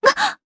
Isaiah-Vox_Damage_jp_b.wav